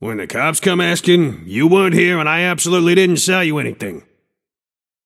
Shopkeeper voice line - When the cops come askin‘, you weren’t here and I absolutely didn’t sell you anything.